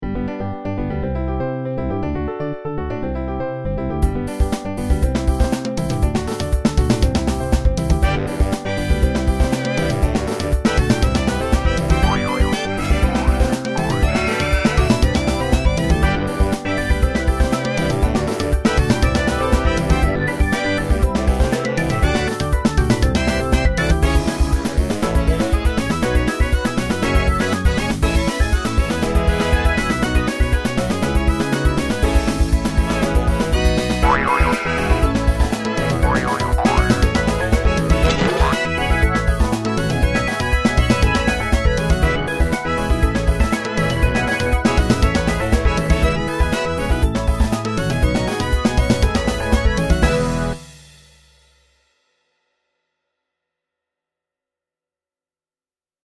HAPPY MUSIC ; EXPERIMENTAL MUSIC